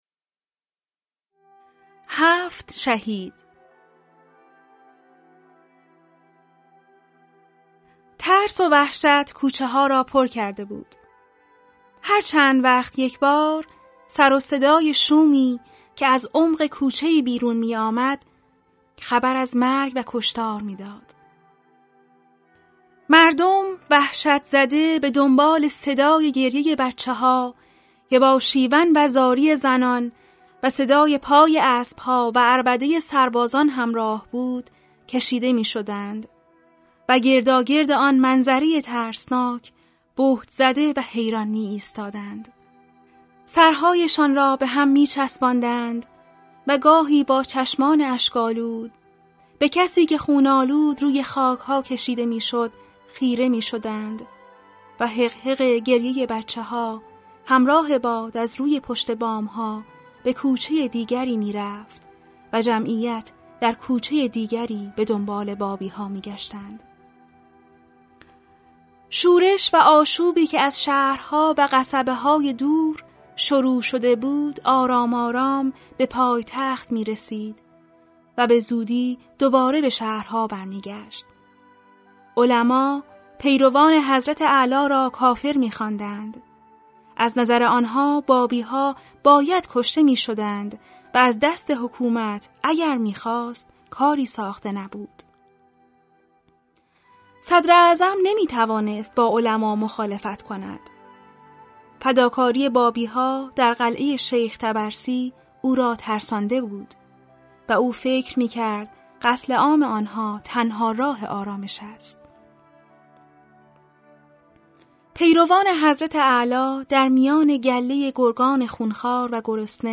کتاب صوتی سالهای سبز | تعالیم و عقاید آئین بهائی